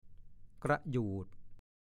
ฐานข้อมูลพจนานุกรมภาษาโคราช